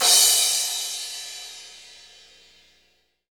CYM CRA370LR.wav